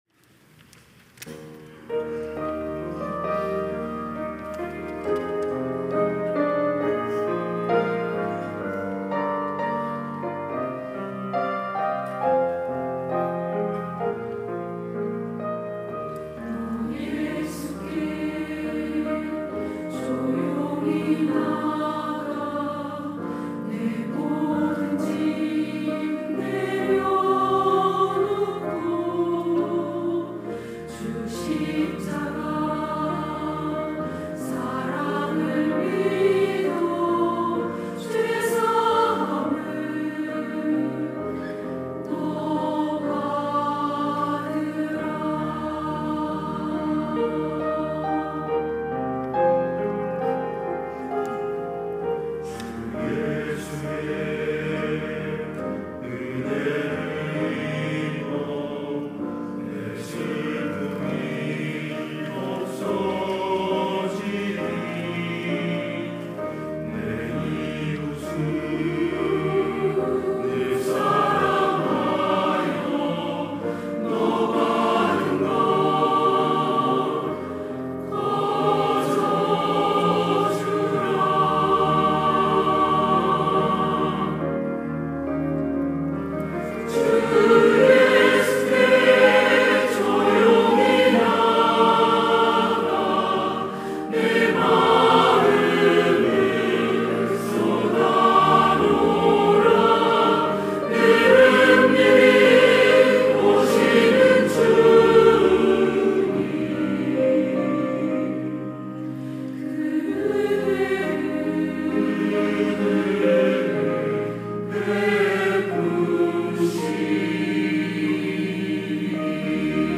시온(주일1부) - 너 예수께 조용히 나가
찬양대